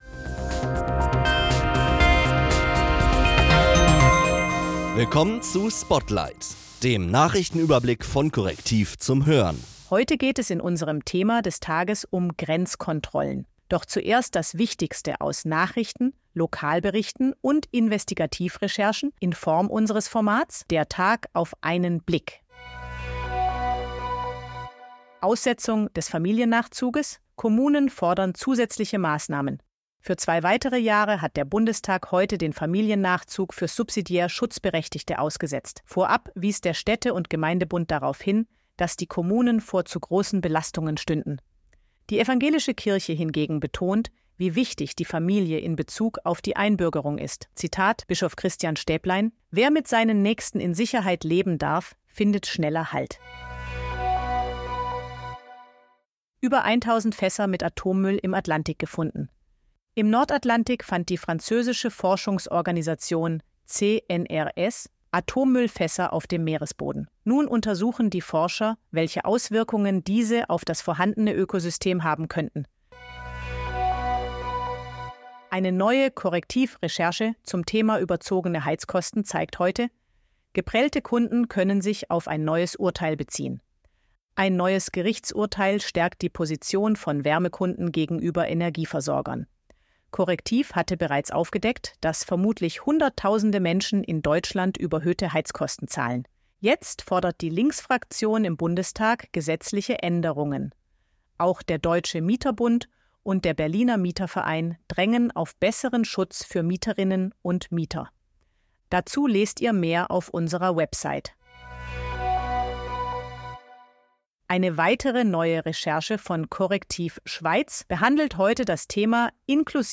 Diese für Audio optimierte Kompaktfassung des täglichen Spotlight-Newsletters ist von einer KI-Stimme eingelesen und von Redakteuren erstellt und geprüft.